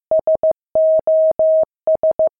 • Una señal S.O.S. consiste en una serie de pulsos cortos y largos que se utilizan para pedir socorro
SOS + pulsos
• Como se ha visto anteriormente, la señal SOS en código Morse consiste en tres pulsos cortos, tres largos y otros tres cortos: ( . . . _ _ _ . . . ) que corresponden a las letras SOS.
SOS.mp3